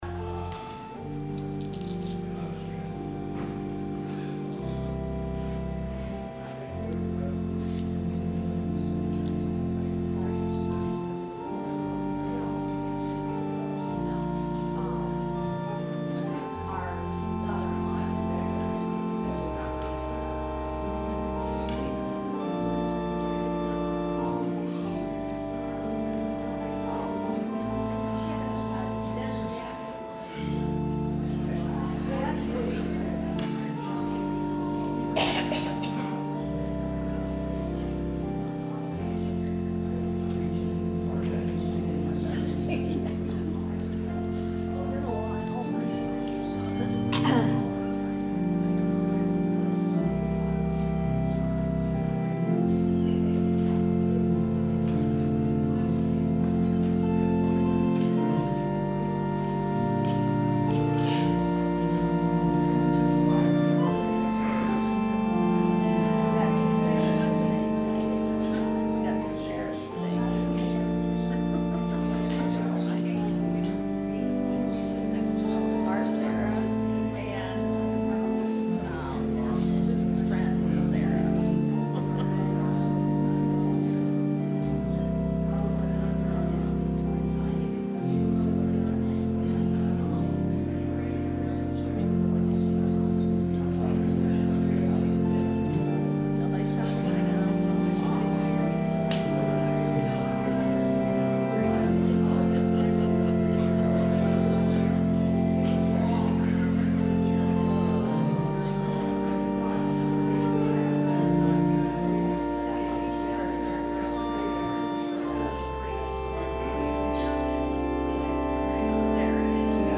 Sermon – October 7, 2018
advent-sermon-october-7-2018.mp3